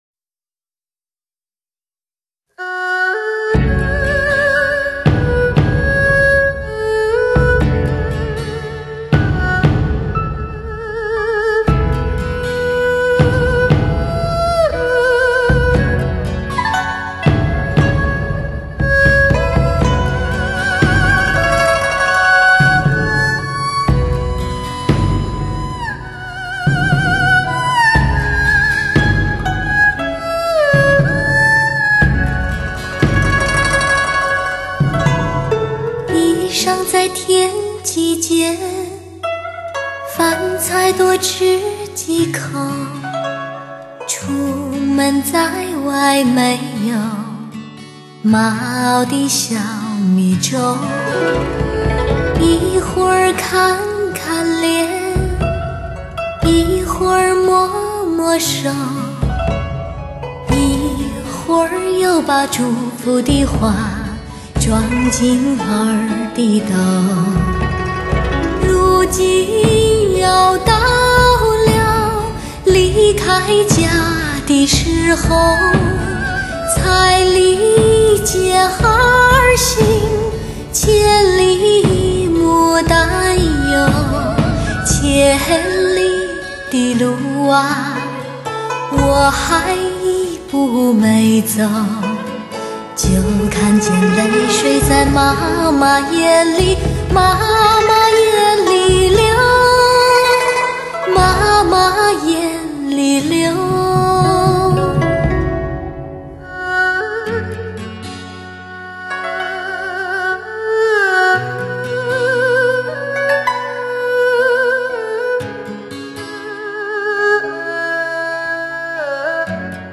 天籁的女声纯洁无瑕，
犹如自然、宁静的月光笼罩着你，